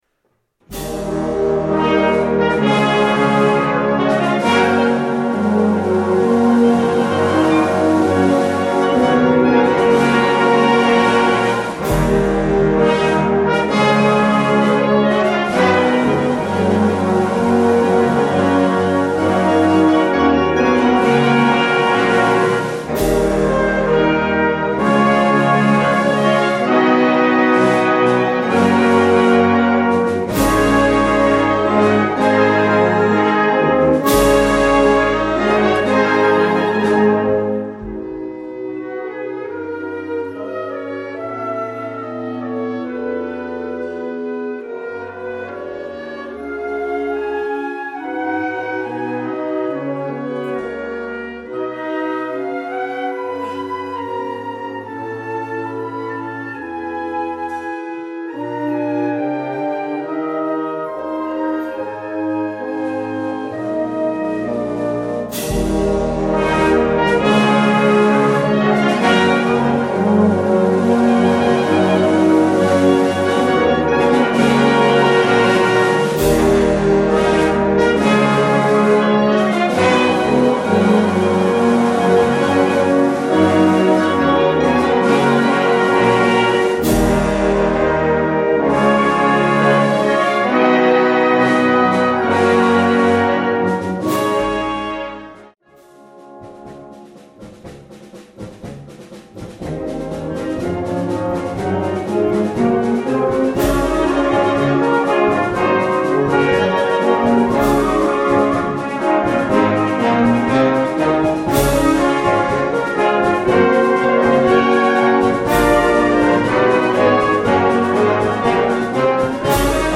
Categorie Harmonie/Fanfare/Brass-orkest
Subcategorie Concertmuziek
Bezetting Ha (harmonieorkest)